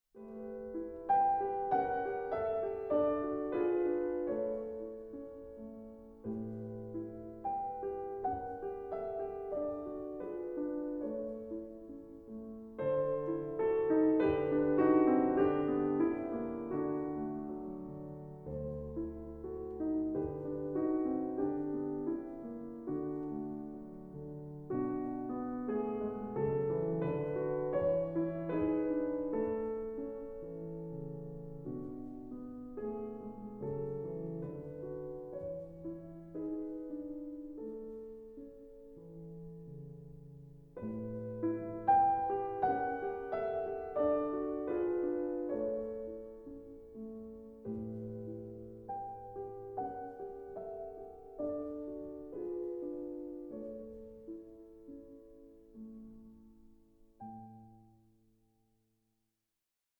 Nicht schnell 4:53